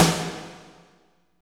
47.07 SNR.wav